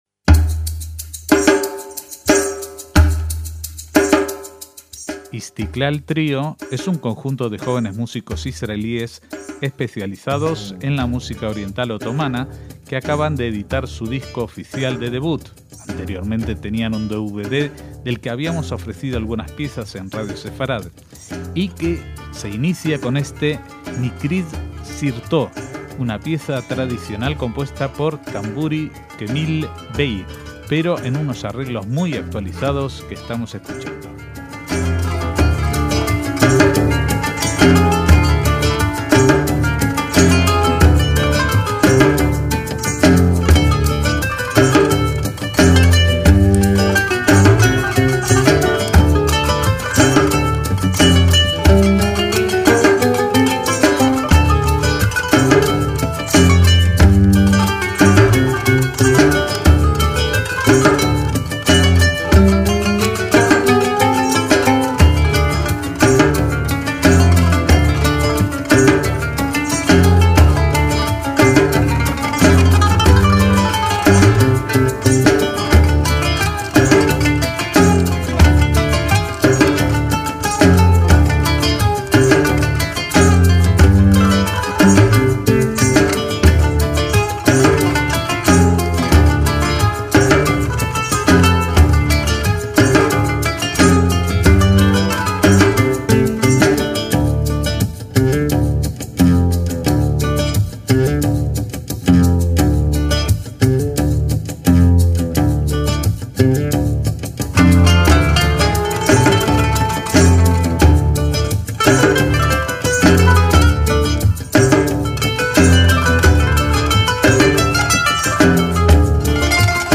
PONLE NOTAS - Istiklal Trio es un conjunto musical israelí que toca música oriental y otomana con un toque moderno y elementos occidentales como la fusión y la música new age para un repertorio étnico.